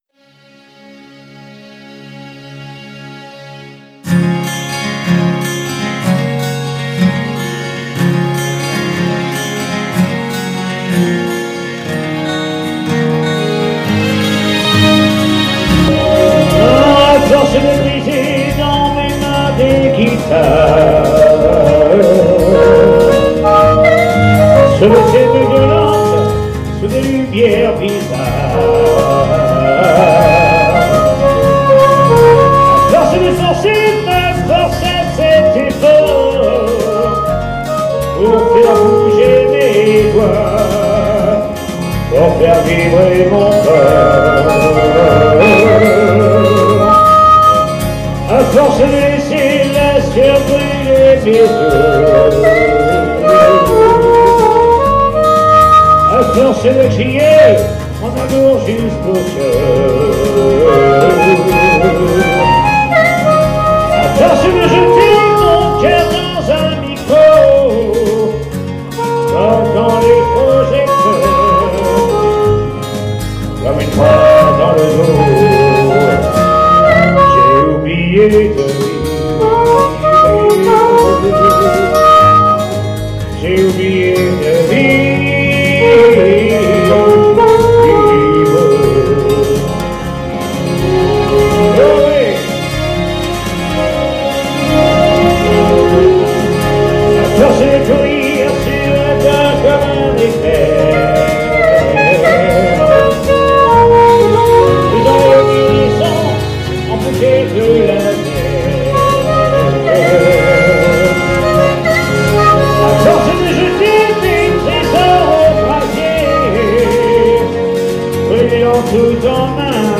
SOIREES BLUES-ROCK RETROSPECTIVE
DUO CHANT/HARMONICA
maquettes